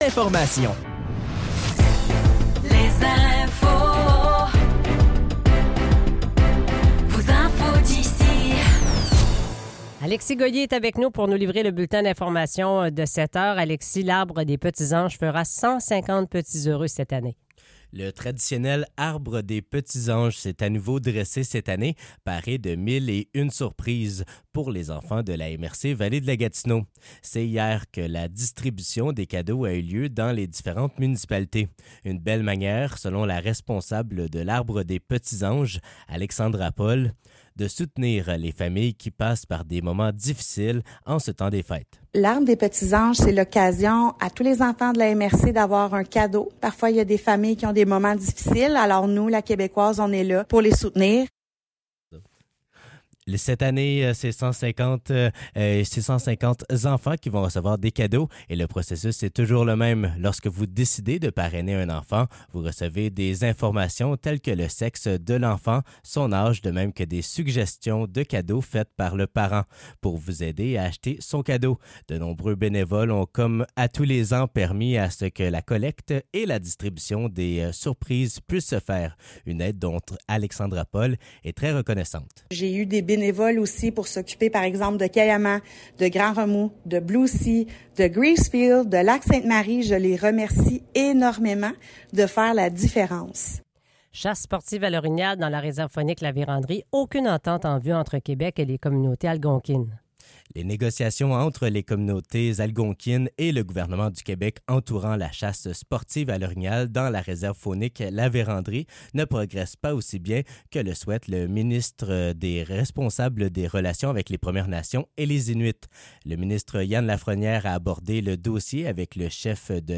Nouvelles locales - 13 décembre 2024 - 7 h